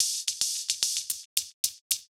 Index of /musicradar/ultimate-hihat-samples/110bpm
UHH_ElectroHatD_110-03.wav